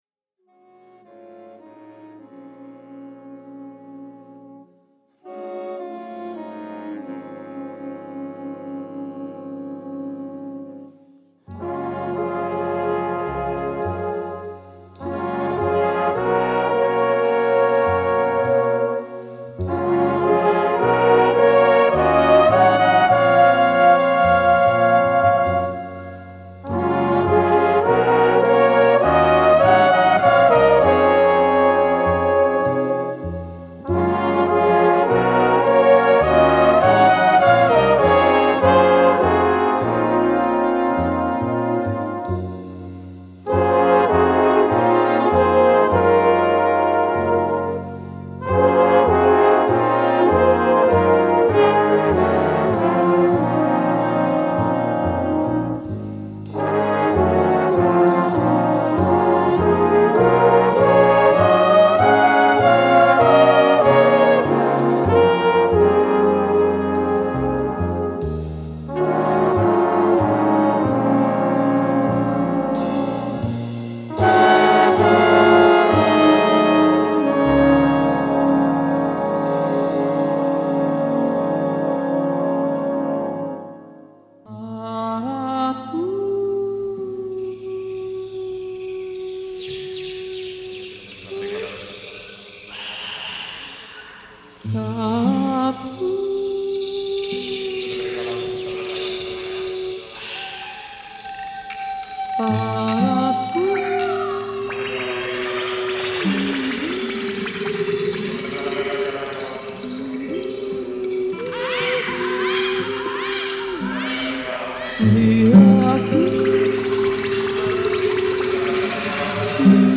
Mellow Groove , Mix CD